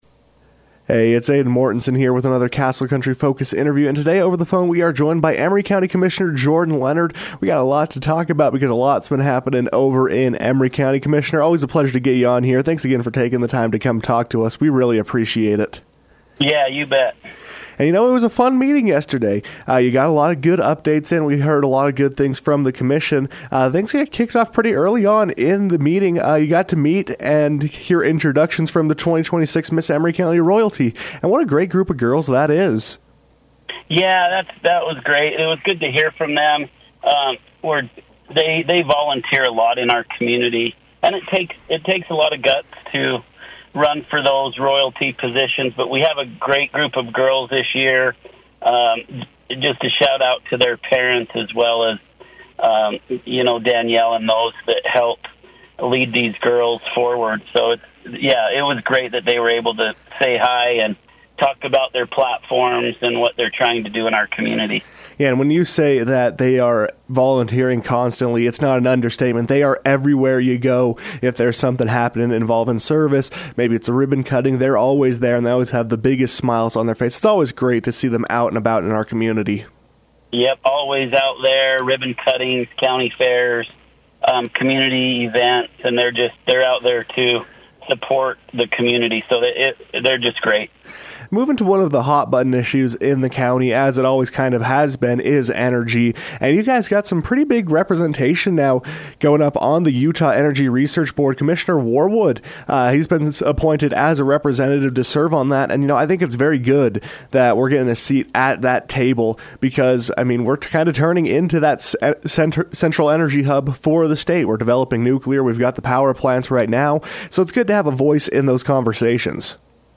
Following the Aug. 5 meeting of the Emery County Commissioners, Commissioner Jordan Leonard joined the KOAL newsroom for his biweekly conversation on what's happening along the San Rafael Swell.